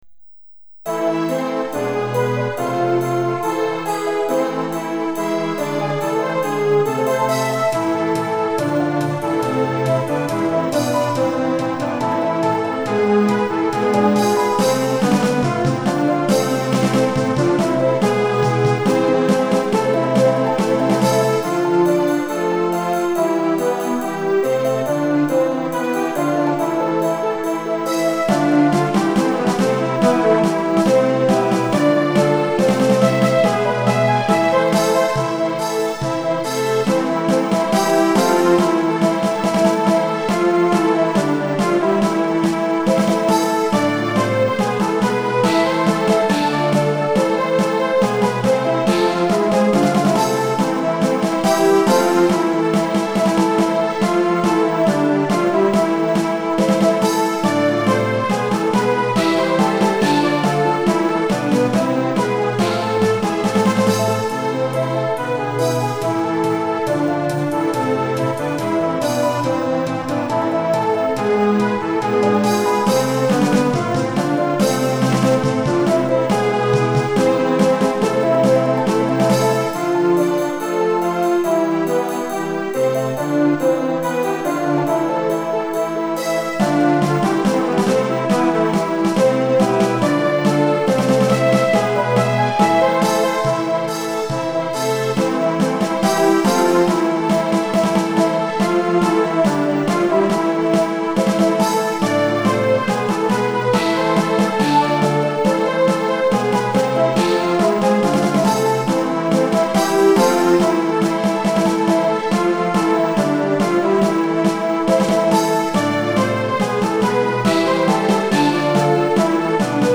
〜カラオケ版〜